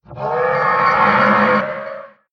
sounds / mob / horse / skeleton / death.mp3